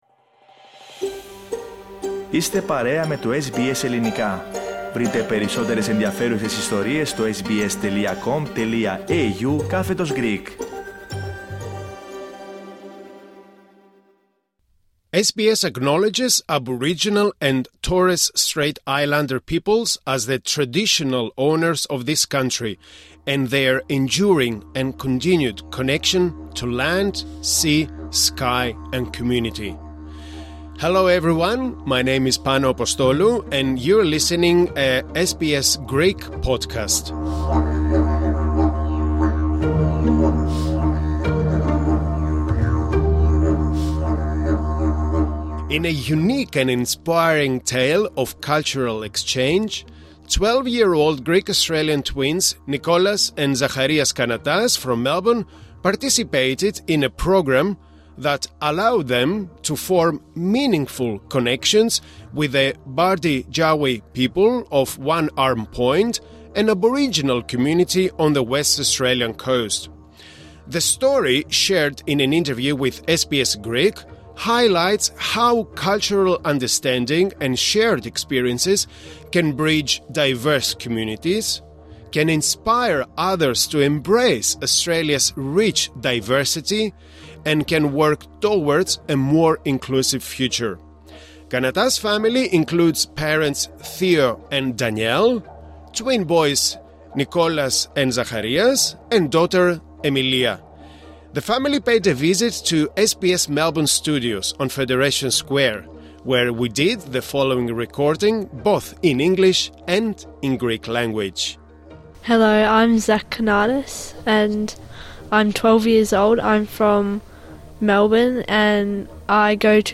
LISTEN TO Bridging Cultures: Greek-Australian twins connect with an Aboriginal community 22:13 The story, shared in an interview with SBS Greek, highlights how cultural understanding and shared experiences can bridge diverse communities, their words inspire others to embrace Australia’s rich diversity and work toward a more inclusive future.
They paid a visit to SBS Melbourne studios where we did the following recording both in English and in Greek language.